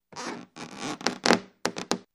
Rubber Stretch